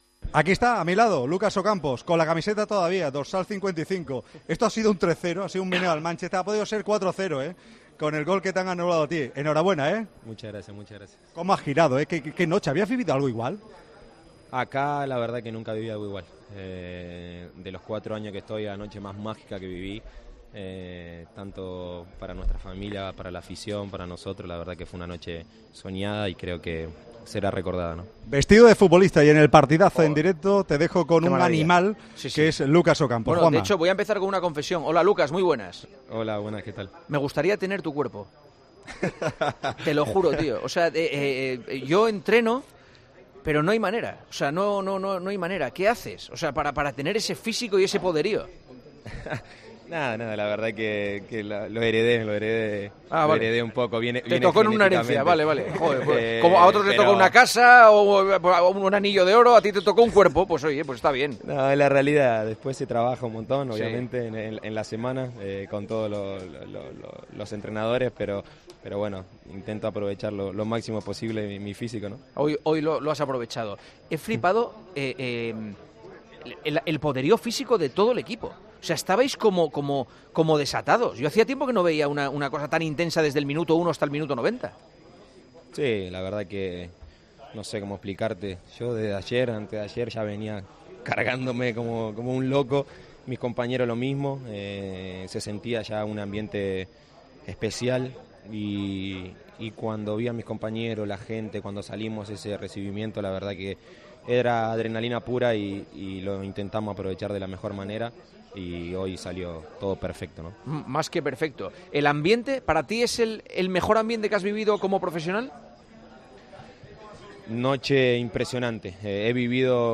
AUDIO: Hablamos con el delantero del Sevilla después de la histórica noche en la que golearon 3-0 al Manchester United para pasar a semifinales.